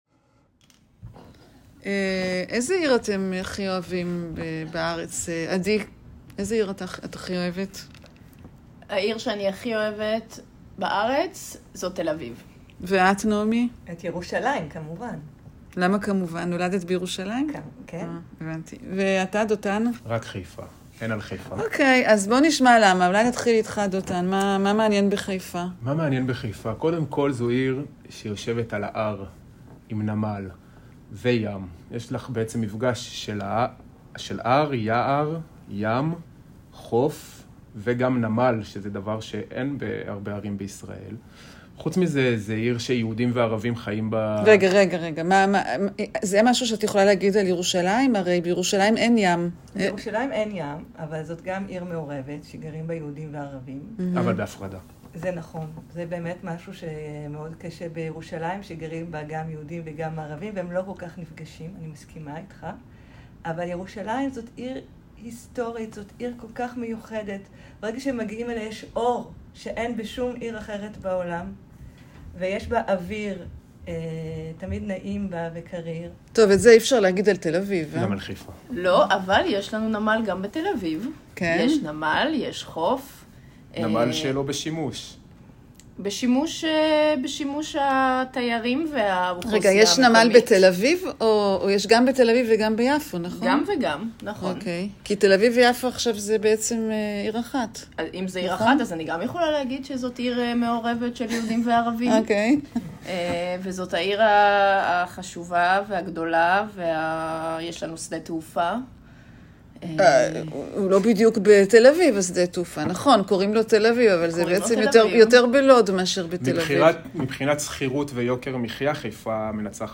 Vous trouverez ici des fichiers mp3 en 14 langues, enregistrés par des locuteurs natifs, libres de droits pour une utilisation pédagogique ou personnelle pour l'entraînement à la compréhension de l'oral en français, anglais, allemand, espagnol, italien, russe, portugais, chinois, occitan, arabe, catalan, corse, créole et hébreu